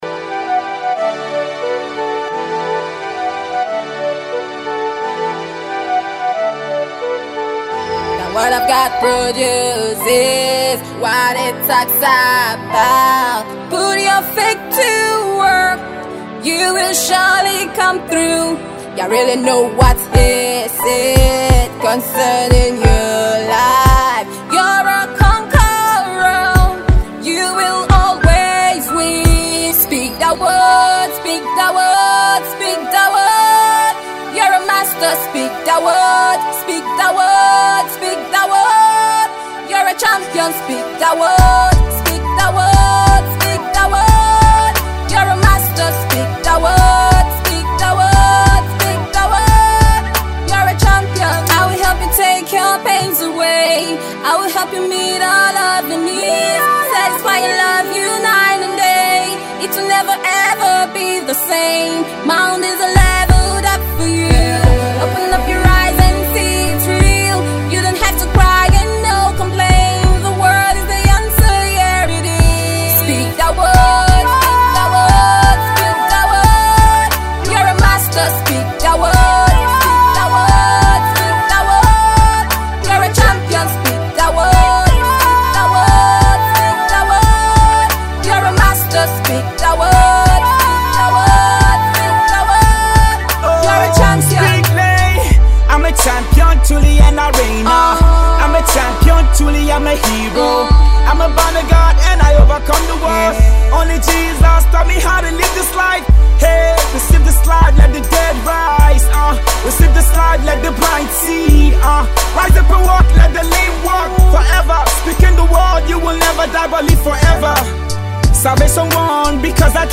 a hipop, R&B, and Afro artiste with deep passion for music